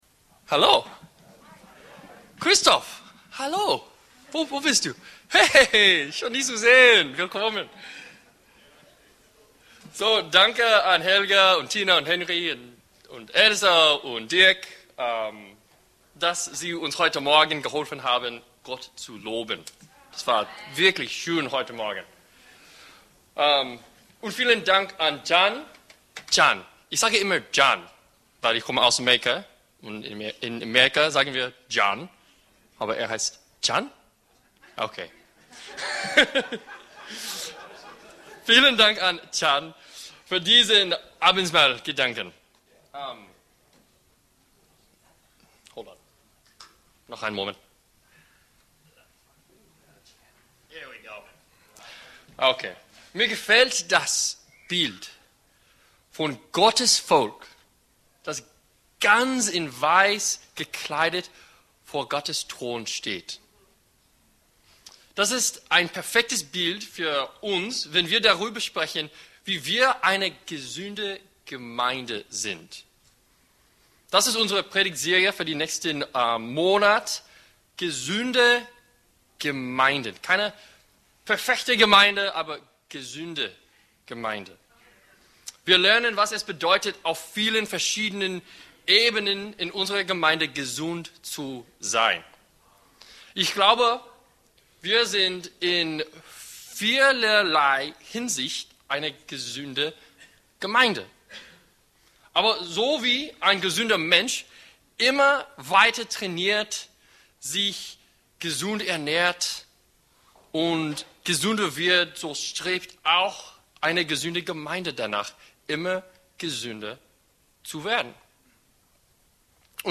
anziehen Prediger